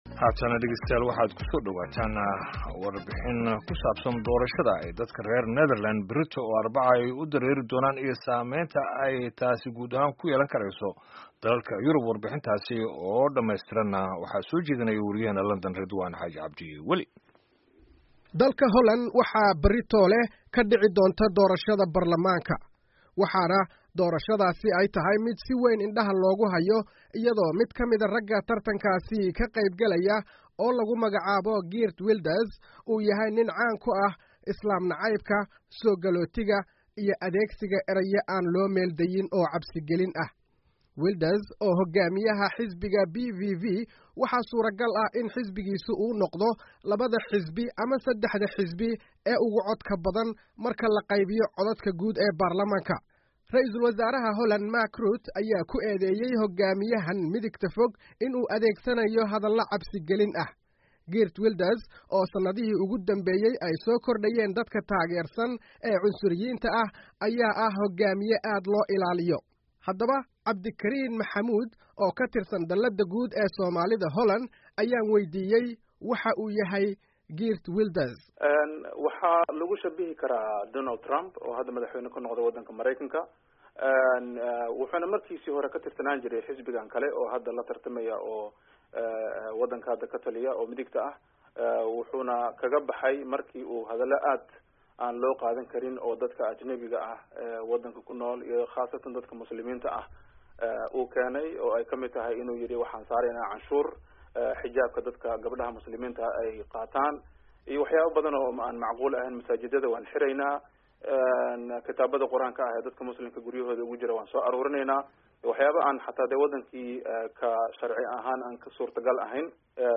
Warbixinta Doorashooyinka Berri ee Holland